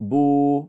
tar det precis lika lång tid som att säga بو (Boo)